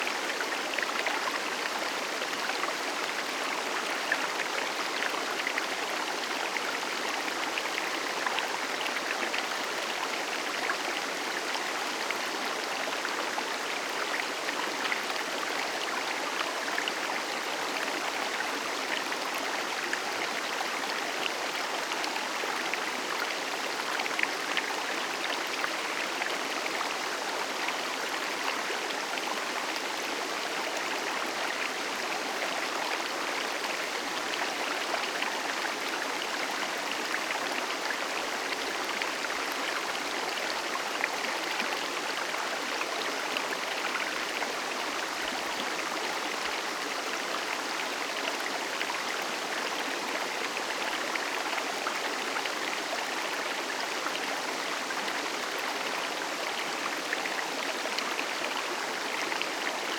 Waterfalls Rivers and Streams
River Stream Loop.wav